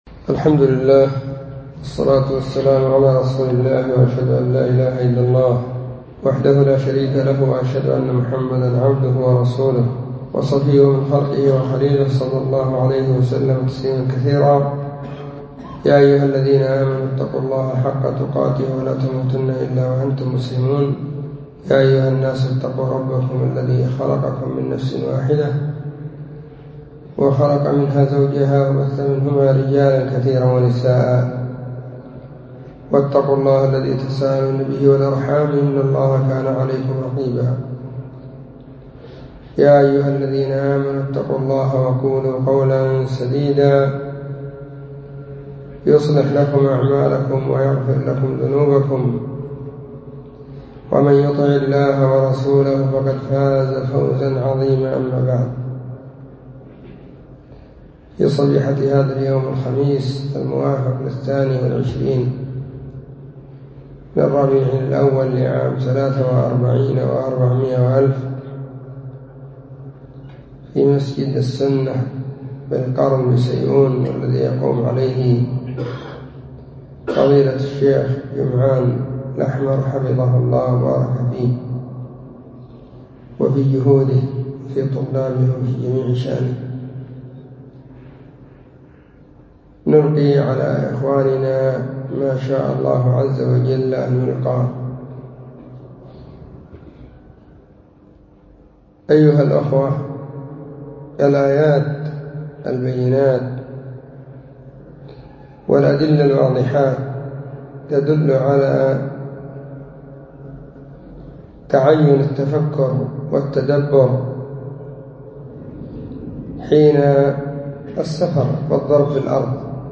*💿كلمة في مركز السنة بالقرن💿*
📢 مسجد – الصحابة – بالغيضة – المهرة، اليمن حرسها الله.